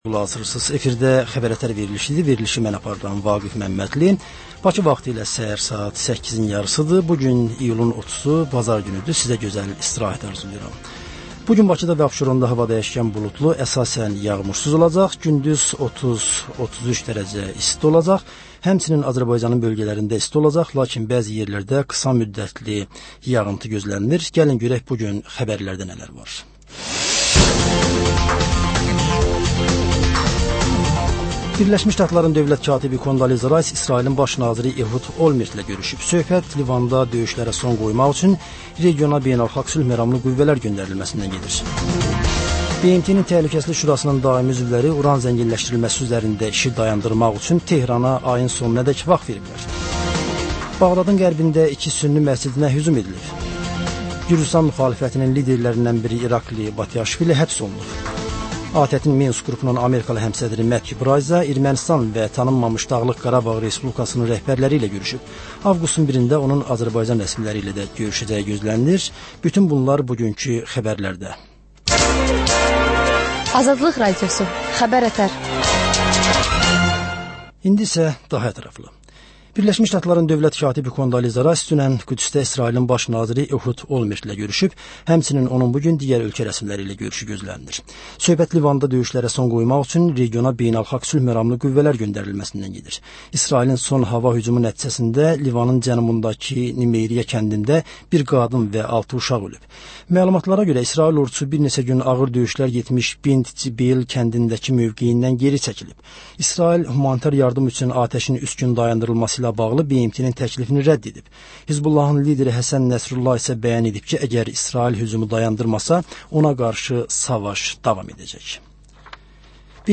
Səhər xəbərləri